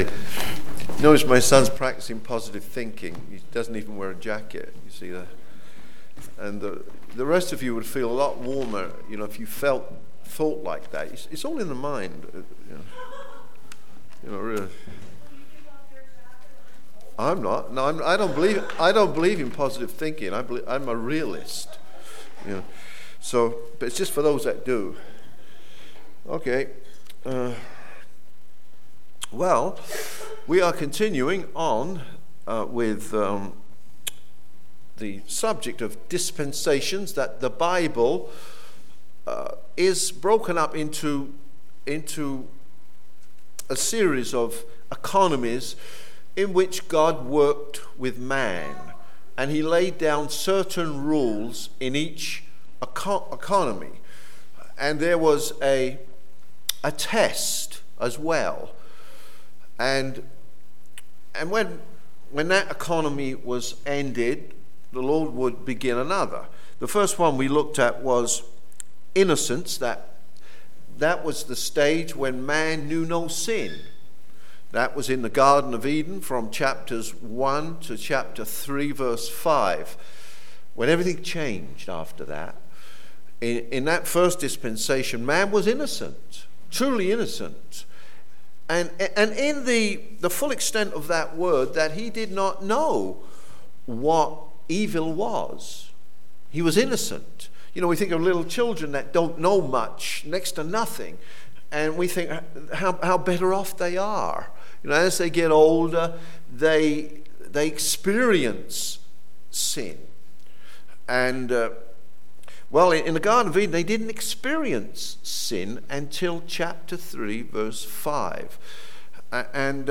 Kamloops, B.C. Canada
Sunday Afternoon Service